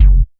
RESO KIK#1.wav